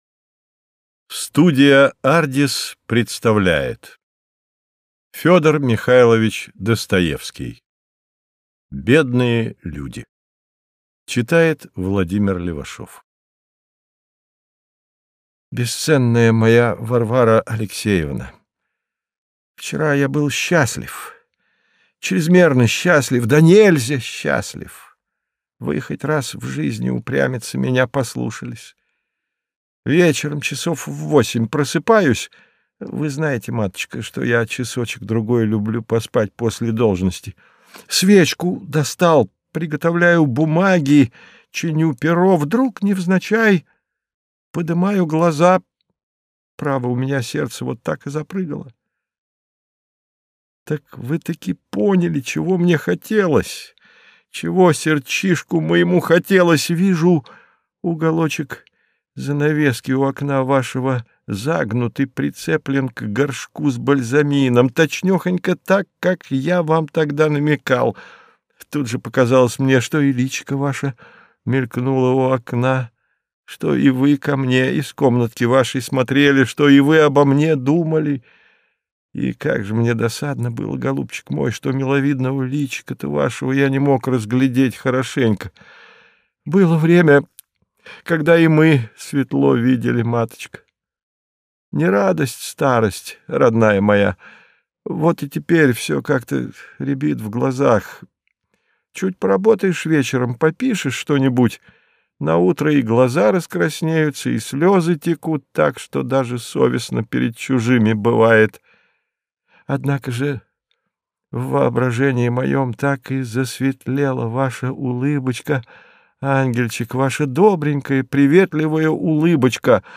Аудиокнига Бедные люди | Библиотека аудиокниг